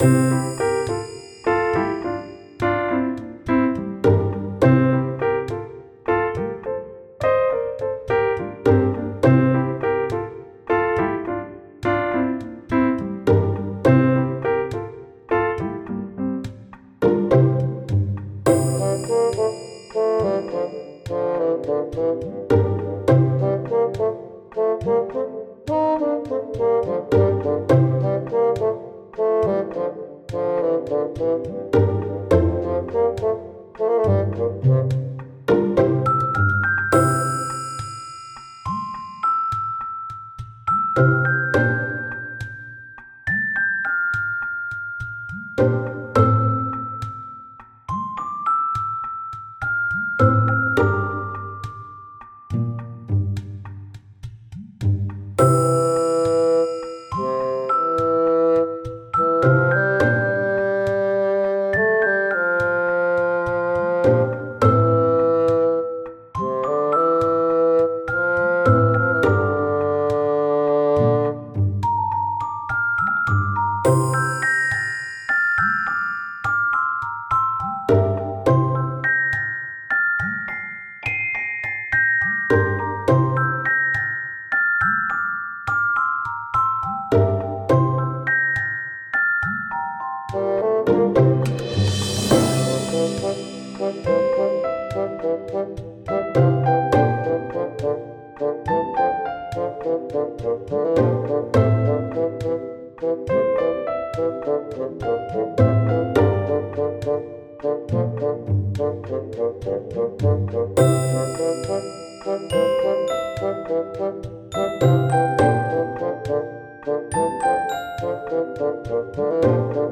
あたたかそうなな感じの森。
フィールド